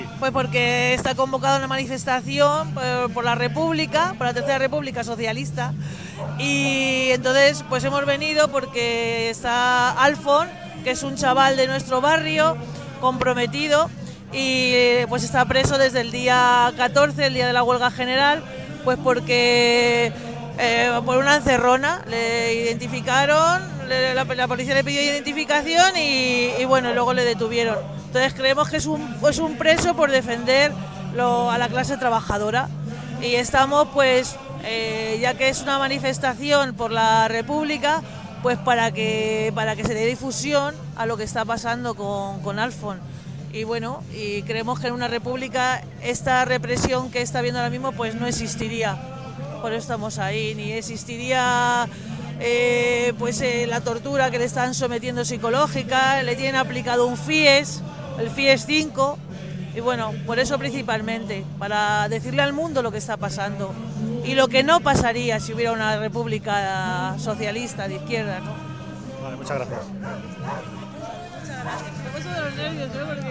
Recorrió desde Sol hasta Plaza Neptuno, con gritos de "los recortes para los de las cortes", "antifascistas" y por la república. Entrevistas a portavoces populares